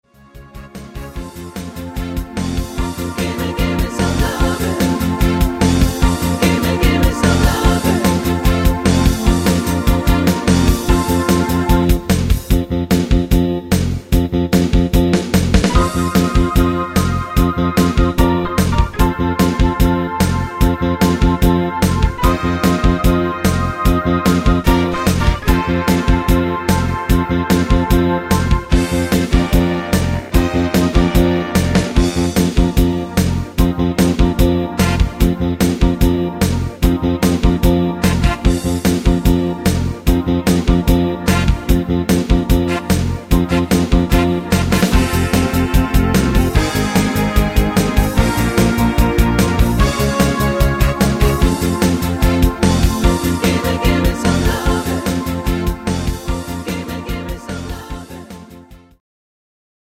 Rhythmus  60-er Beat
Art  Oldies, Englisch